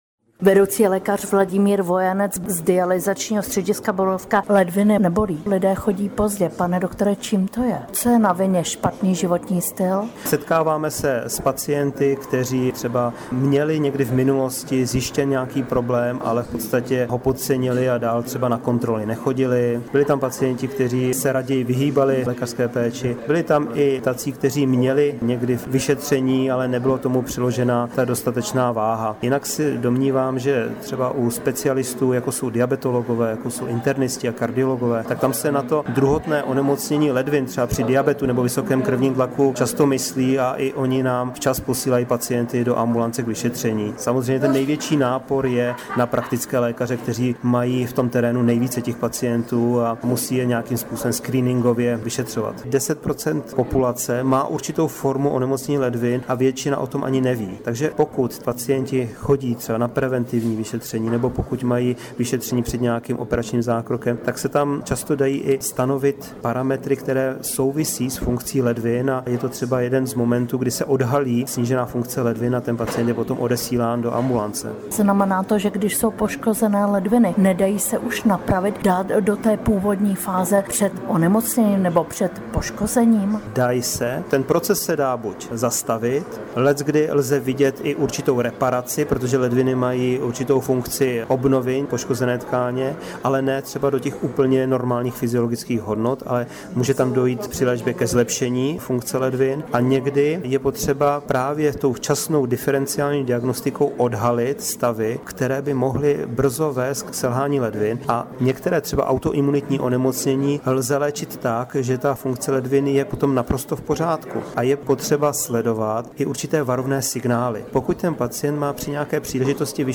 Rozhovor s odborníky o nemocech ledvin
Poslouchejte v našem rozhovoru odborníky, a také příběh pacienta, který prodělal selhání ledvin.